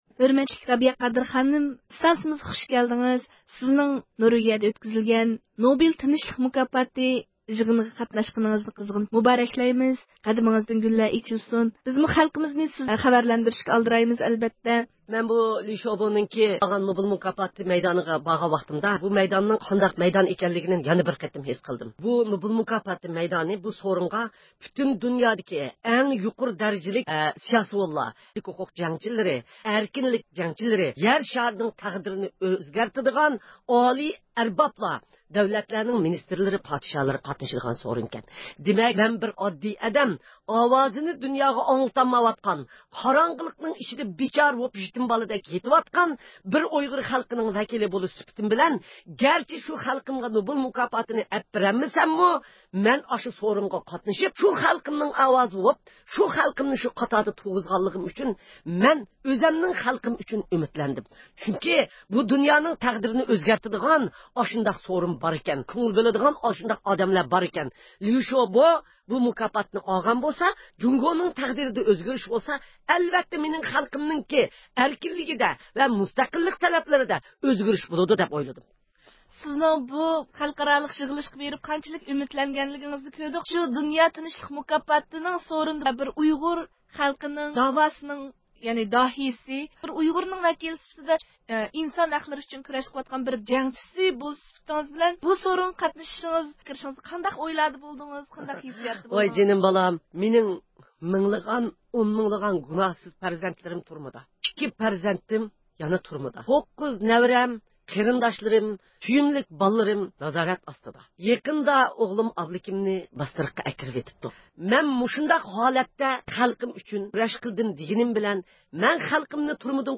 ئۇ 13 – دېكابىر ئىستانسىمىزغا كېلىپ، نوبېل تىنچلىق مۇكاپاتى تارقىتىش مۇراسىمىدىكى مۇھىم دەقىقىلەردىن ئۇيغۇر خەلقىنى خەۋەرلەندۈرۈشكە ۋە بۇ جەرياندىكى تۇيغۇلىرى بىلەن ئورتاقلىشىشقا ئالدىراۋاتقانلىقىنى بىلدۈردى.